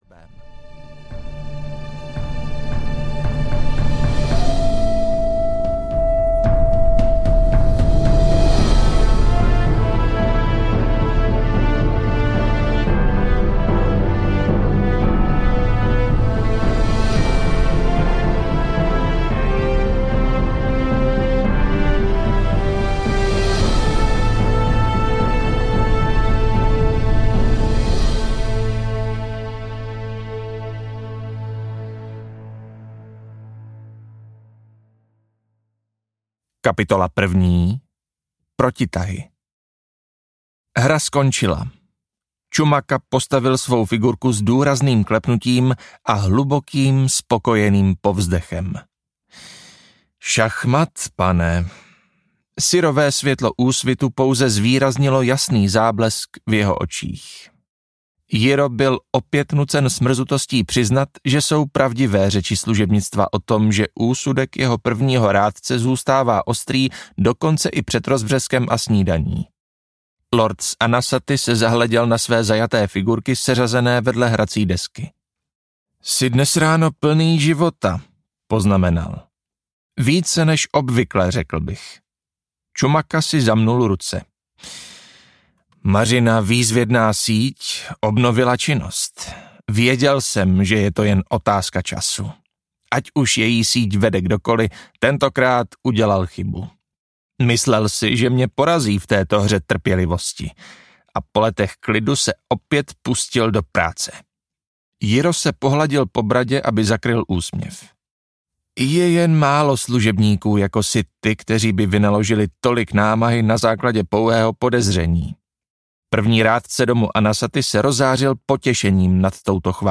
Správce impéria: Císař audiokniha
Ukázka z knihy